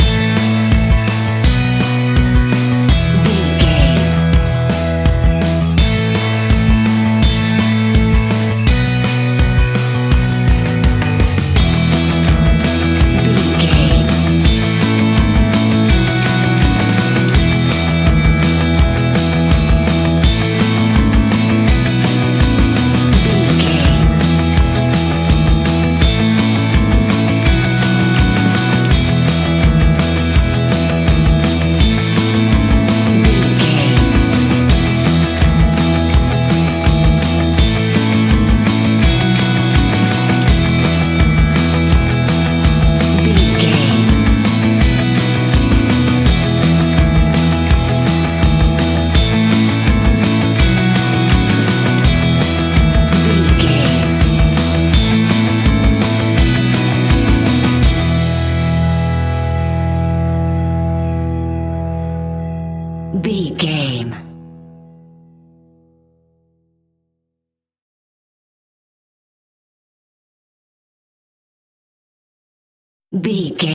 Ionian/Major
pop rock
indie pop
fun
energetic
uplifting
upbeat
rocking
groovy
guitars
bass
drums
piano
organ